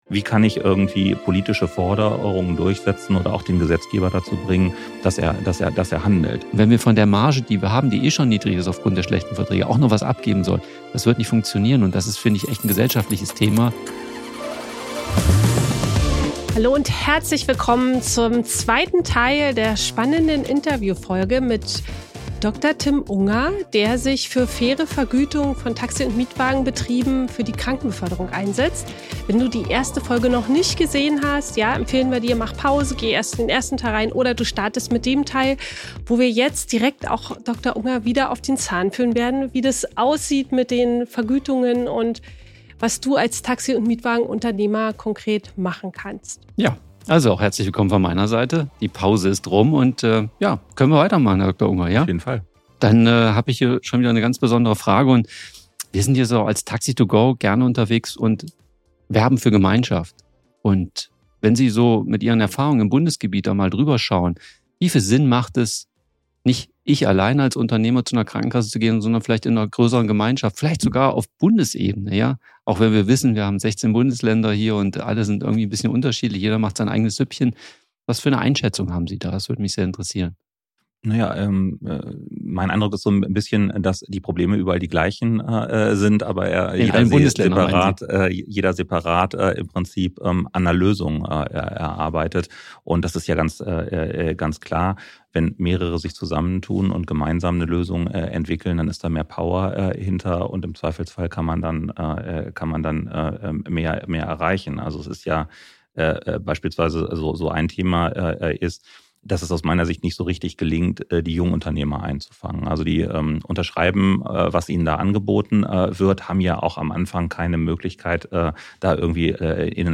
Das ist Teil 2 unseres Interviews